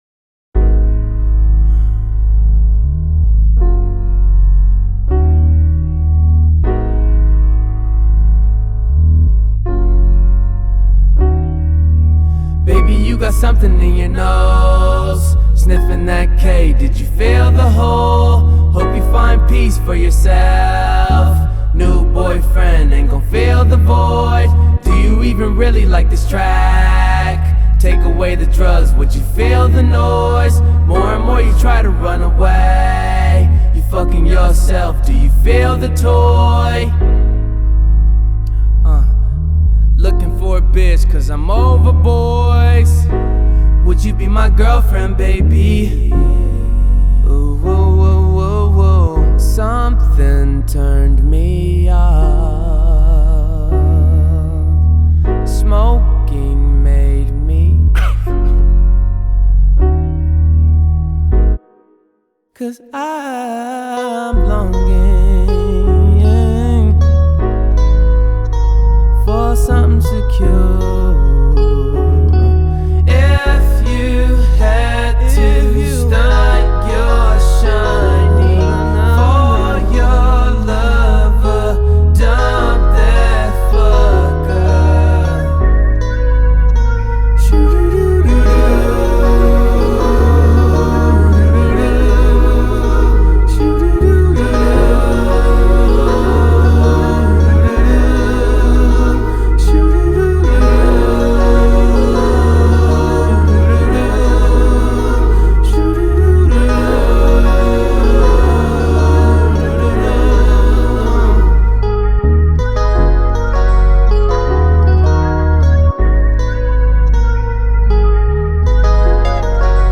R&B Funk